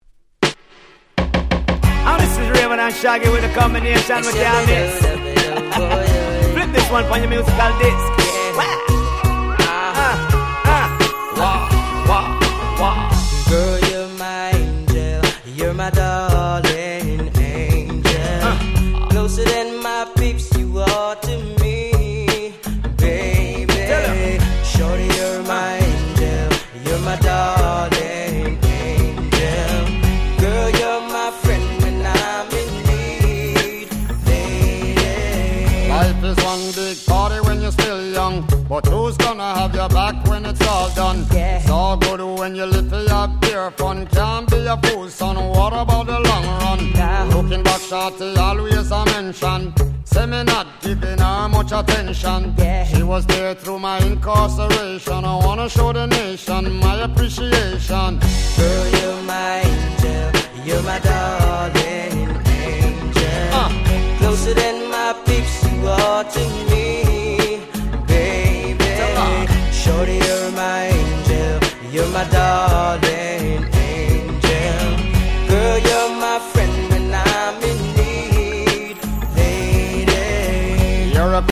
00's レゲエ ラヴァーズ　キャッチー系